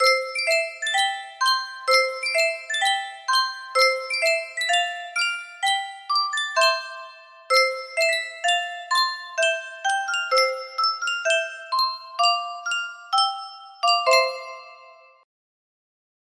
Sankyo Music Box - English Country Garden ASZ
Full range 60